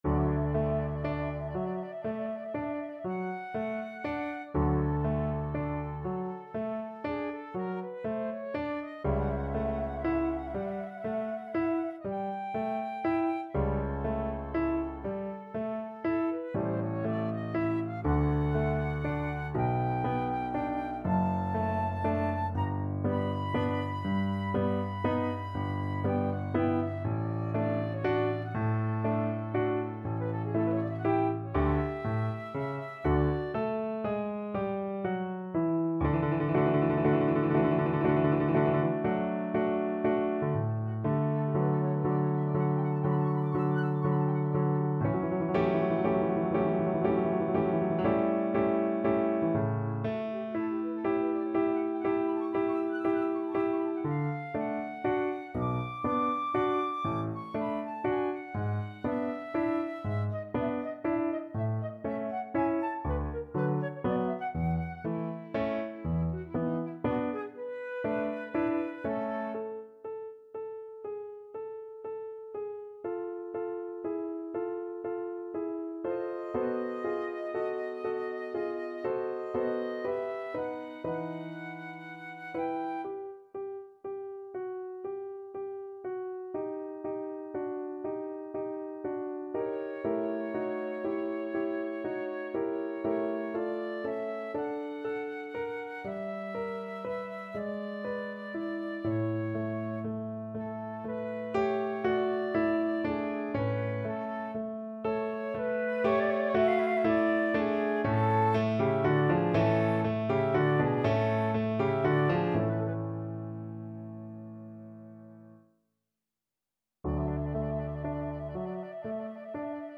9/8 (View more 9/8 Music)
Adagio grazioso
Flute  (View more Advanced Flute Music)
Classical (View more Classical Flute Music)